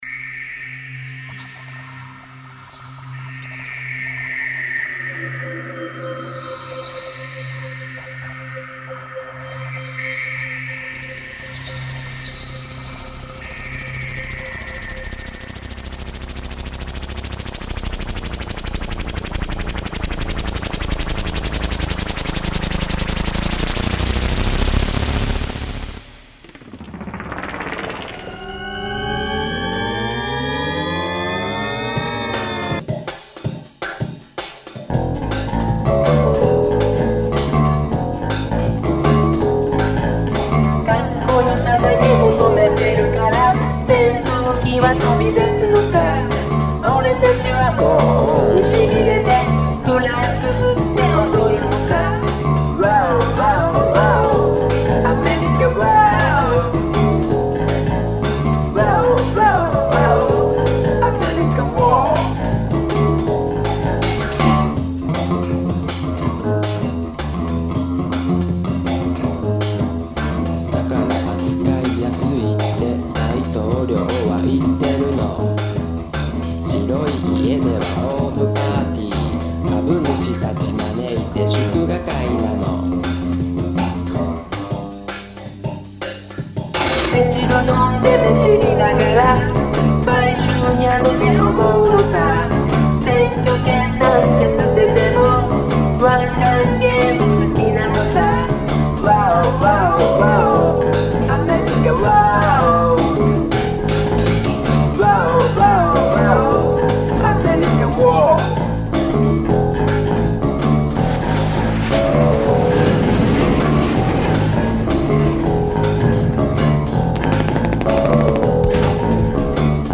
File under: Unsuitable Rock / Electro-Pop